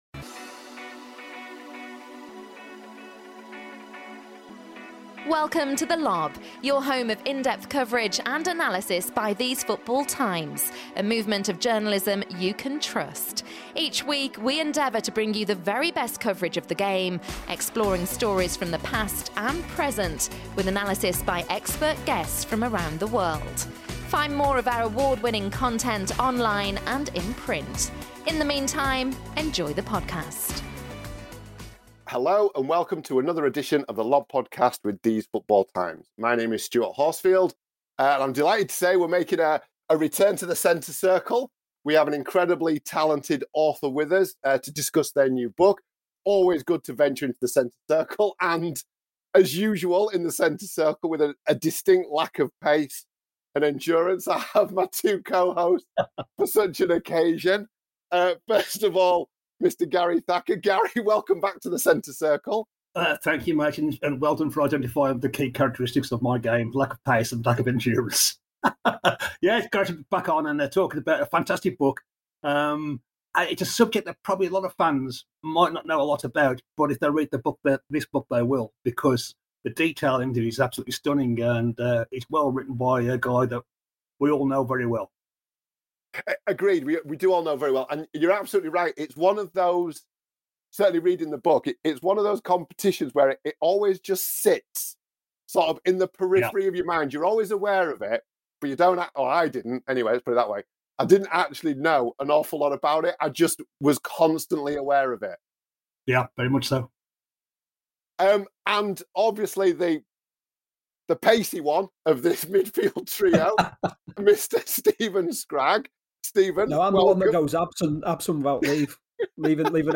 We’re back in the Centre Circle for another book chat.